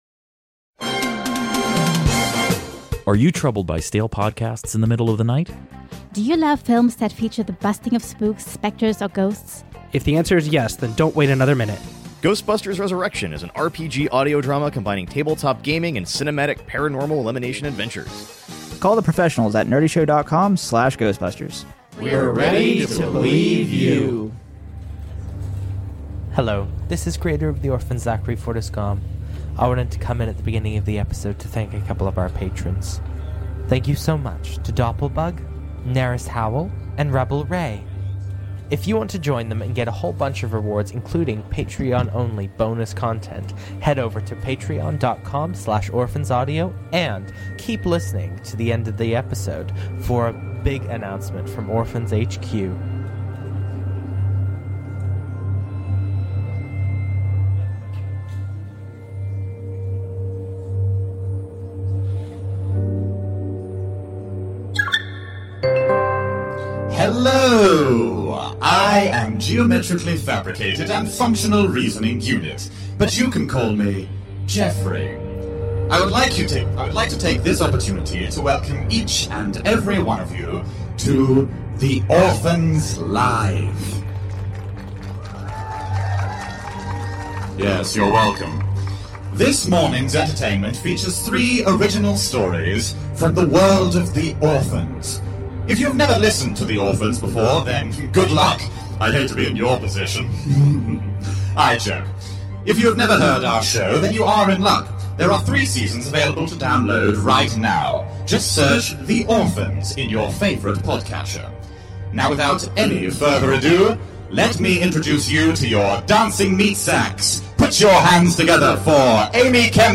These three short tales expand our series universe, exploring the wider world and the clones within it. We took our studio production to the stage in front of a live audience at PodUK in Birmingham.